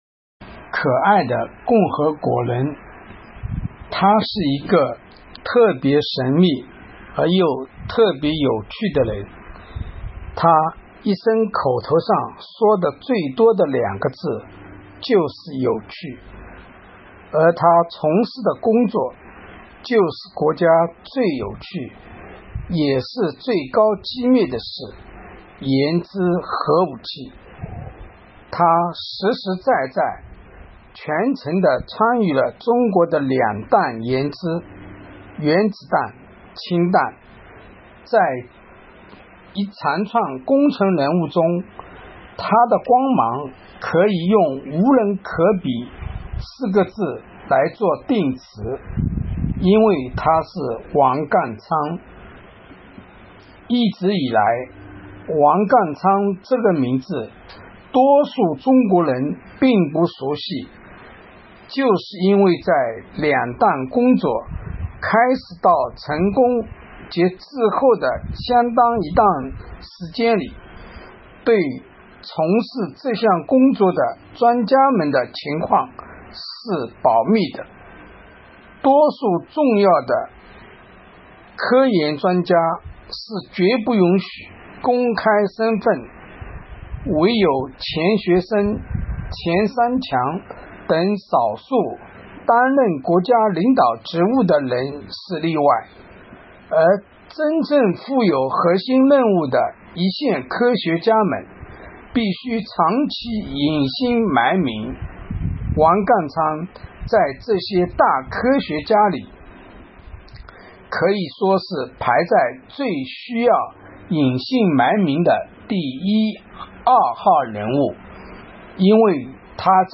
诵读音频：点击阅读